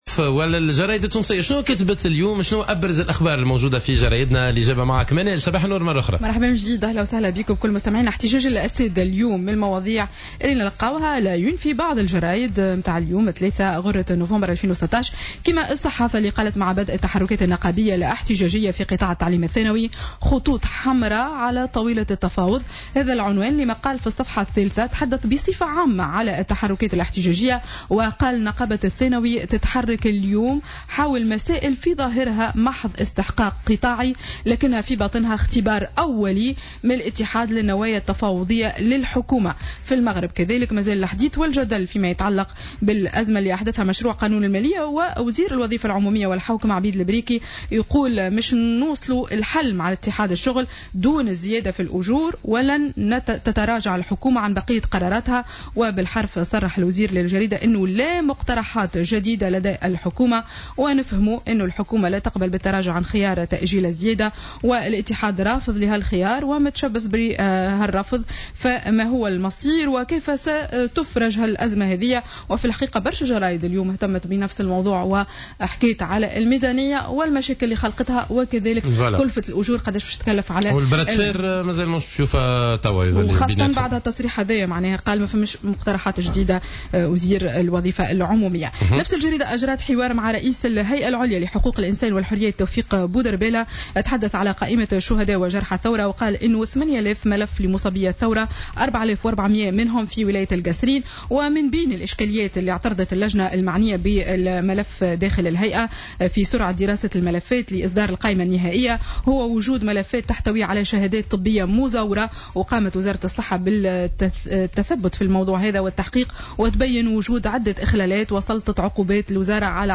Revue de presse du mardi 1er novembre 2016